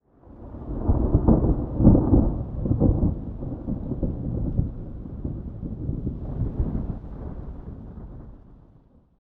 pre_storm_1.ogg